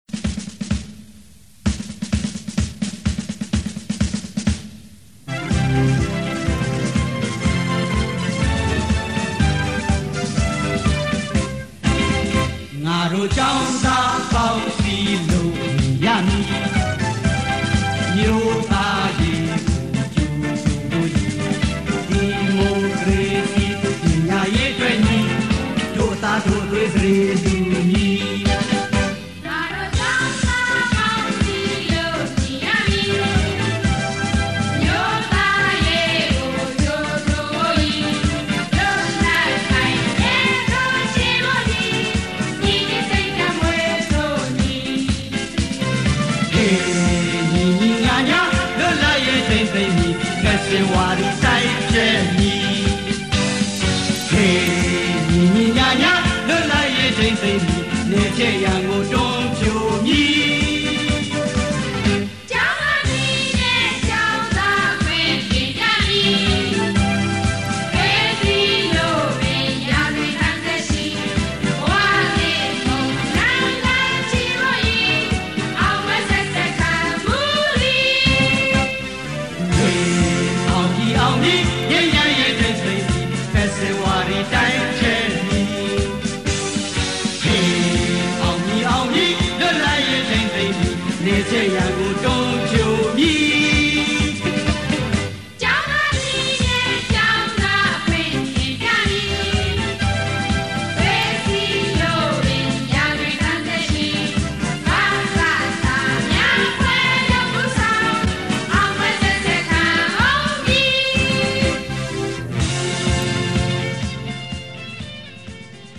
သီချင်း